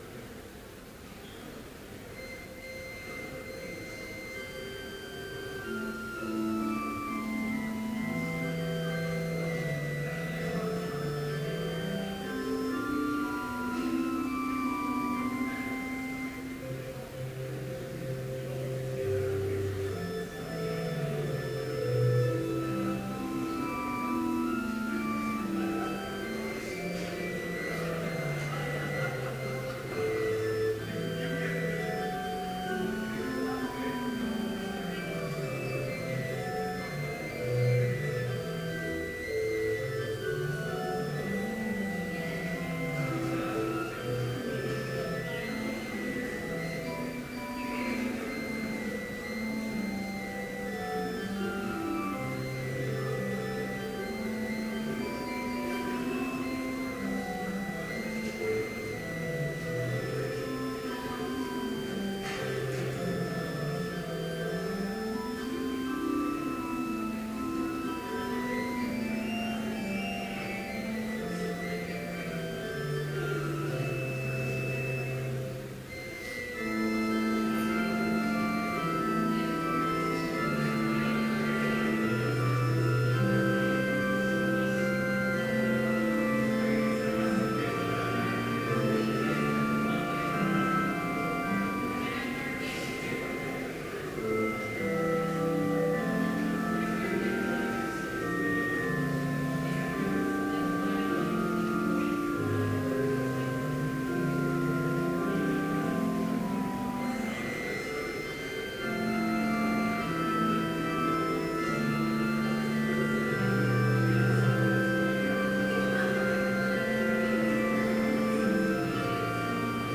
Complete service audio for Chapel - March 26, 2014